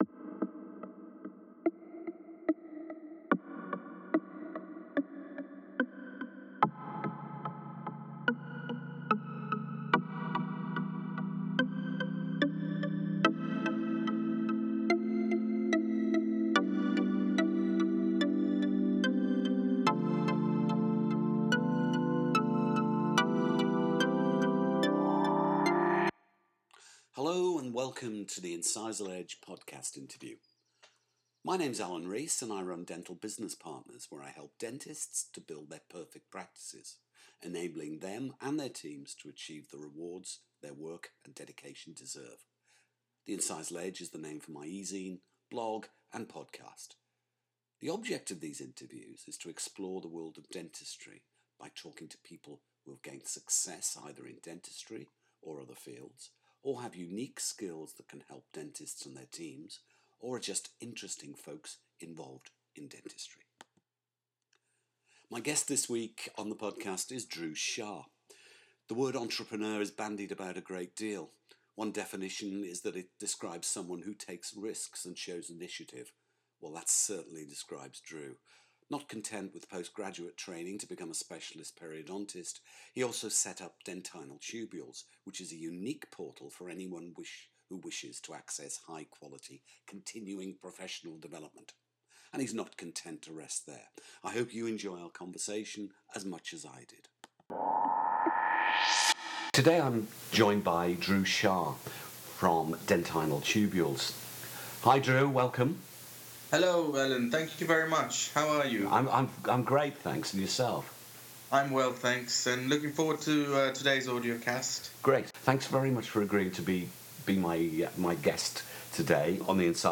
The Incisal Edge Podcast Interview #3